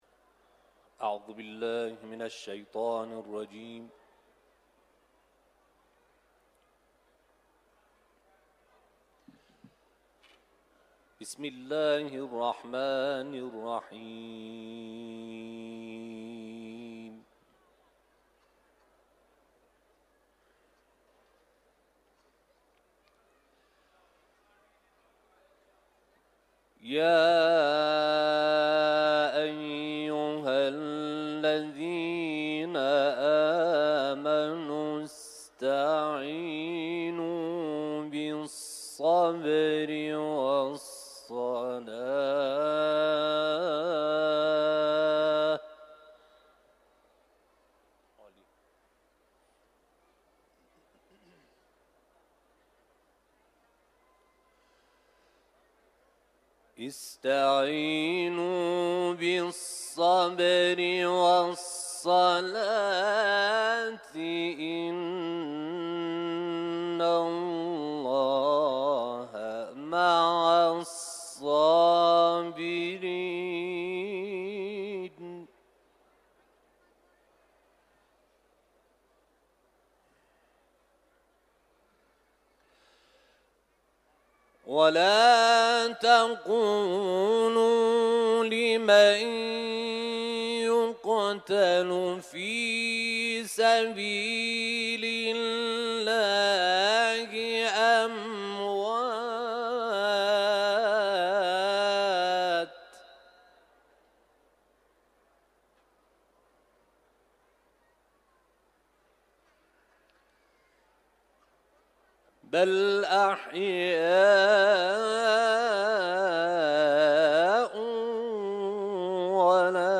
تلاوت
حرم مطهر رضوی ، سوره بقره